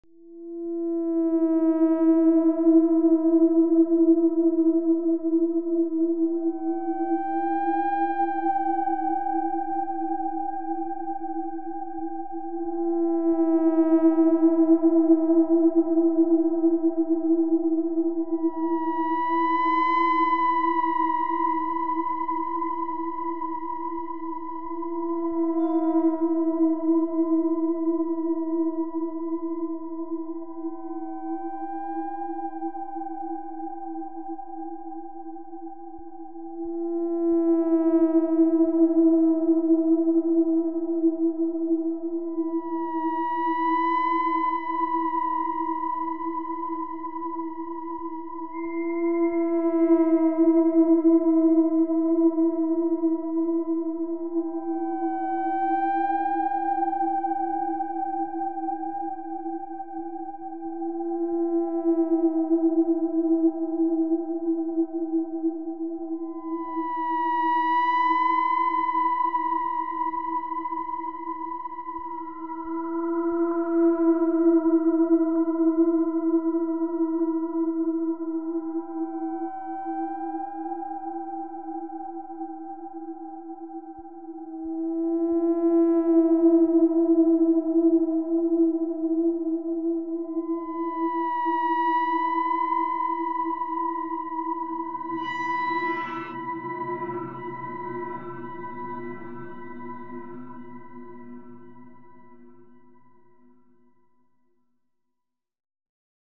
From an old chest, there is an old bakelite record. Someone found a digital version of it — can you discover what’s hidden inside?